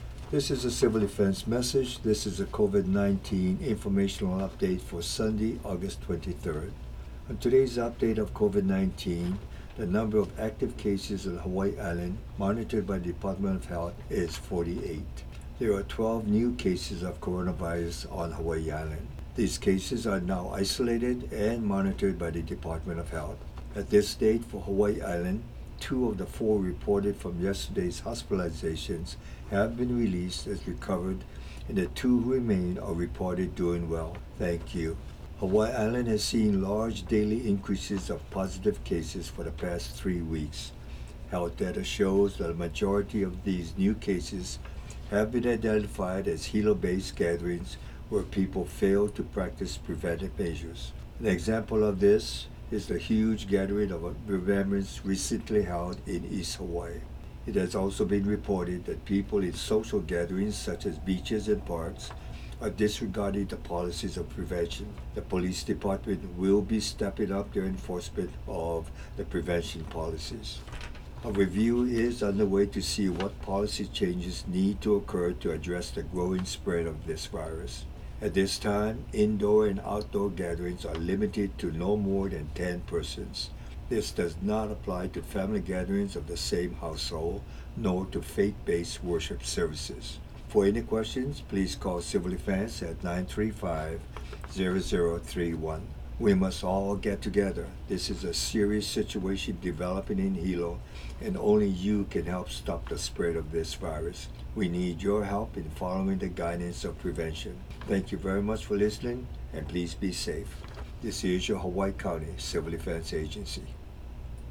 Today’s radio message was issued later than it is normally issued, and featured the voice of Hawaiʻi County Mayor Harry Kim.